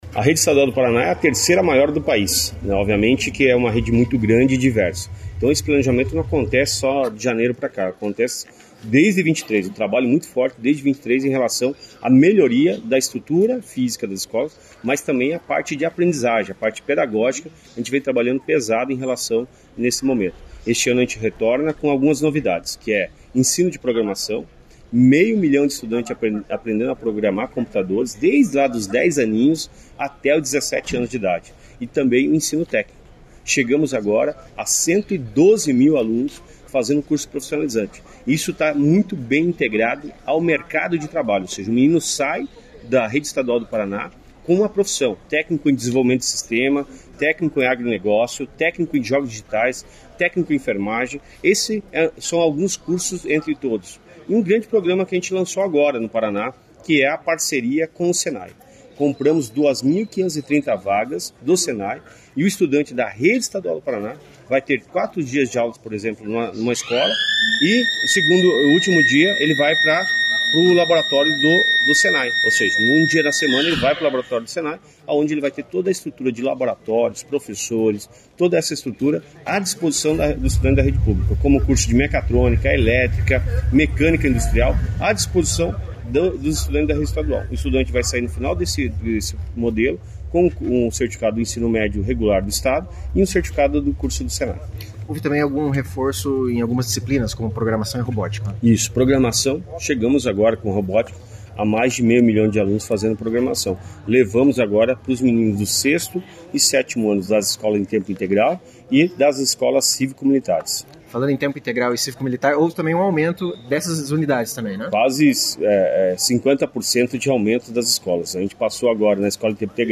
Sonora do secretário da Educação, Roni Miranda, sobre o investimento de R$ 361 milhões na educação do Paraná